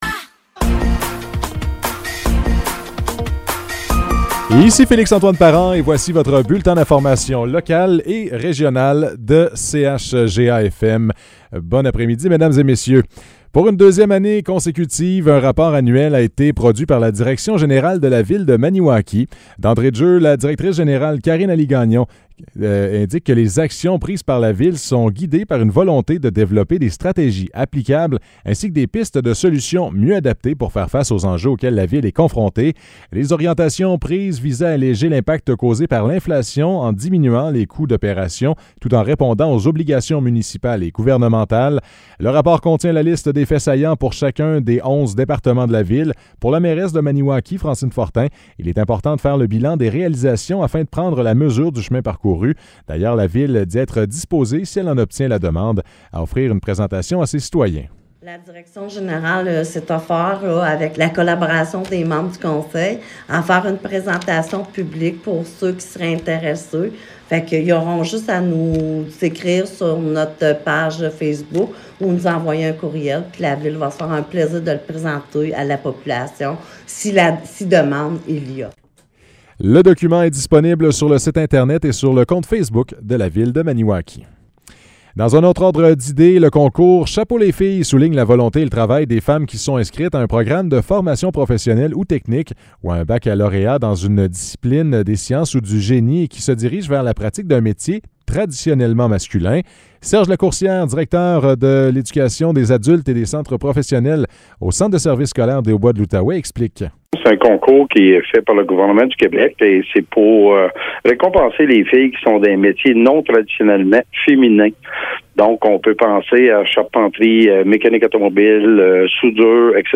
Nouvelles locales - 7 février 2023 - 15 h